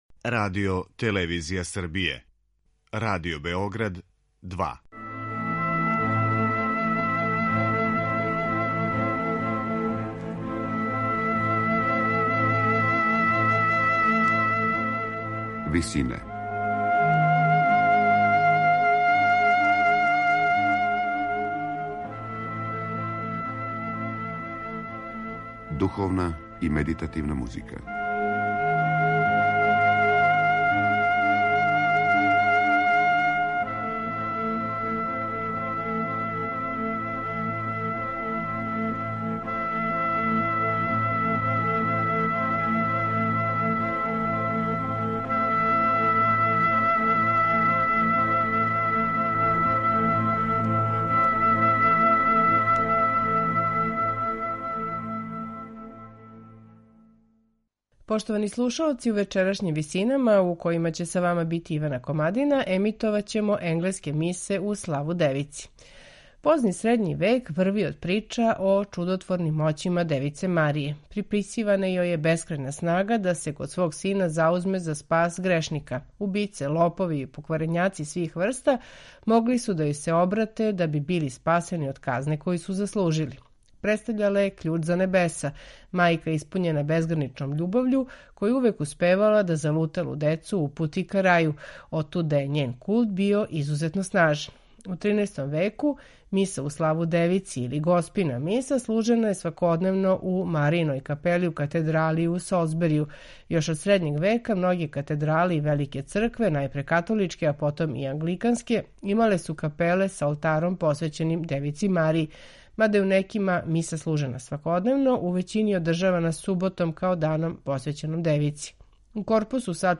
Извесно је да су Kyrie и Gloria и извођени у пару, док су сви остали ставови највероватније били замењивани тропама. Миса се по правилу завршавала мелизмима на текст Ite missa est . Ансамбл Anonymous 4 сакупио је двадесетак мелодија које су могле да се нађу на некој од ових миса и њихову интерпретацију ових записа слушамо у вечерашњим Висинама .